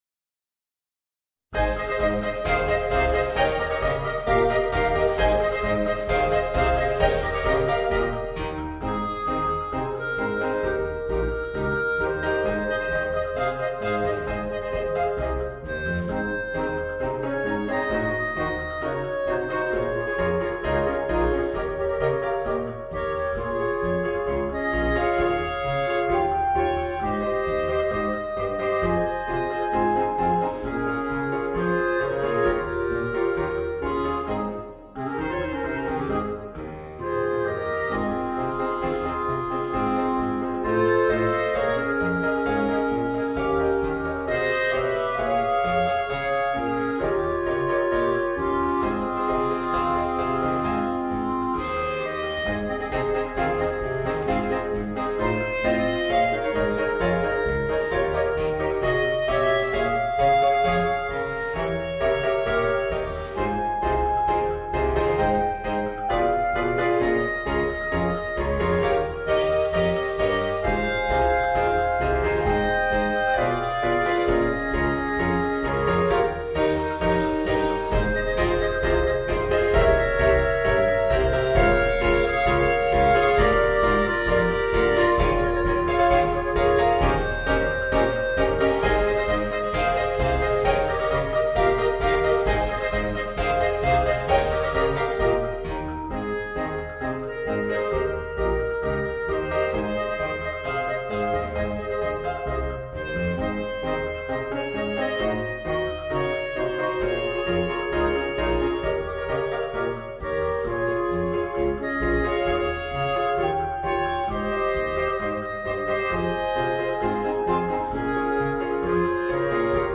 Voicing: 4 Clarinets and Piano